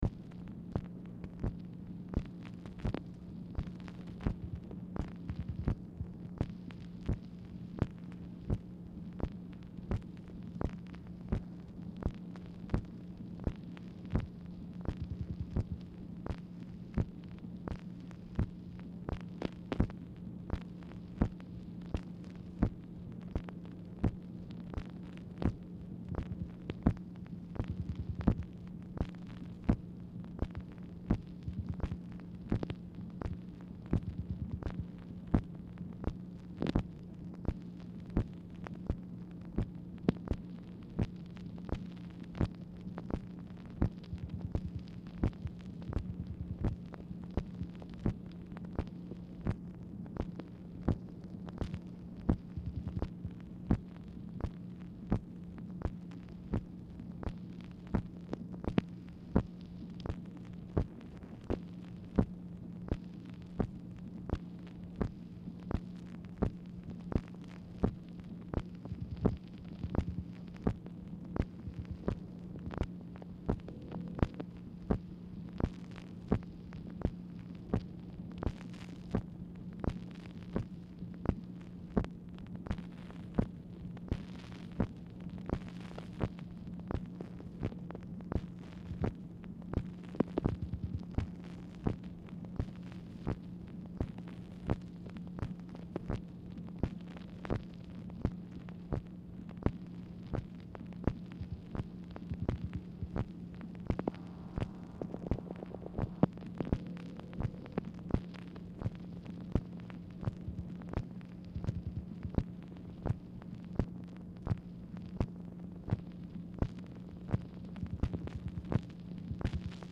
Telephone conversation # 3306, sound recording, MACHINE NOISE, 5/1/1964, time unknown | Discover LBJ
Format Dictation belt
Specific Item Type Telephone conversation